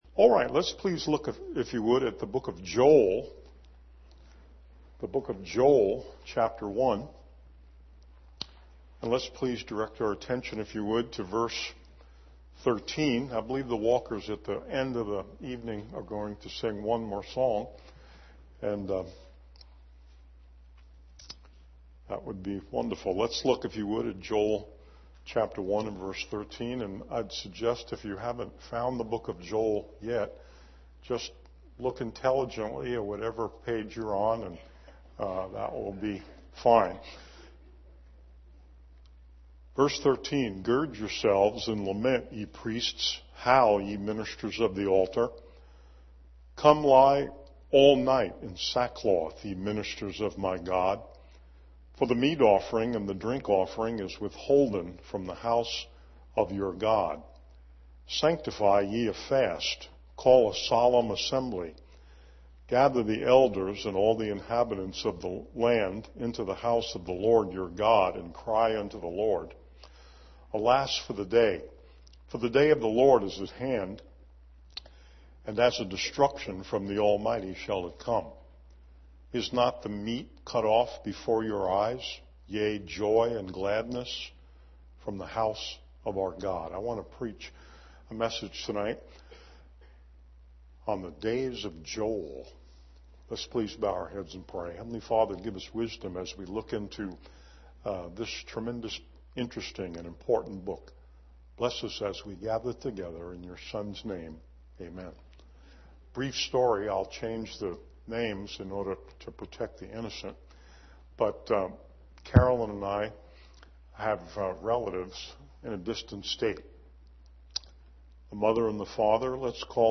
sermon9-15-19pm.mp3